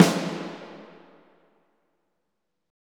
Index of /90_sSampleCDs/Roland LCDP03 Orchestral Perc/SNR_Orch Snares/SNR_Huge Snares